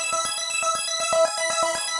SaS_Arp02_120-E.wav